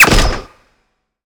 sci-fi_weapon_rifle_laser_shot_02.wav